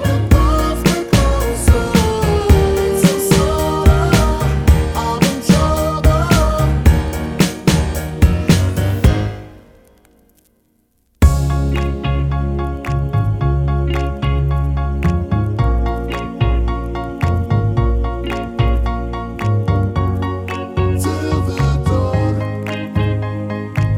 Down 3 Semitones Duets 3:14 Buy £1.50